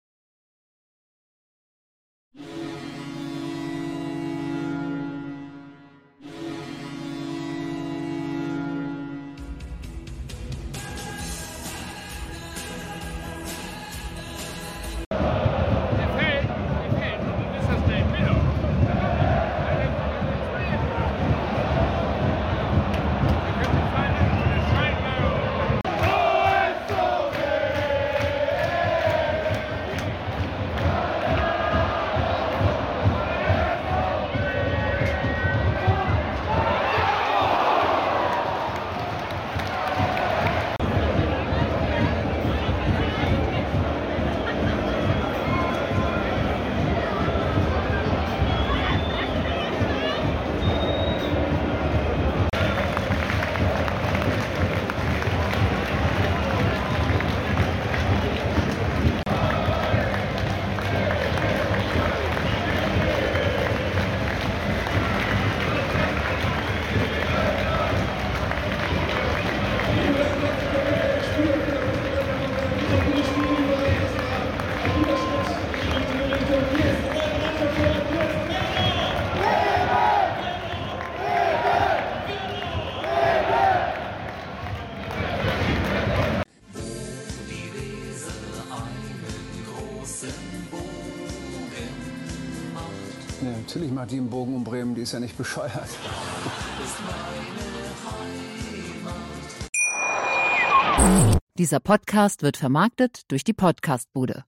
Werder Bremen vs. FC St. Pauli - Impressionen aus dem Stadion
- IMPRESSIONEN AUS DEM STADION
werder-bremen-vs-fc-st-pauli-impressionen-aus-dem-stadion.mp3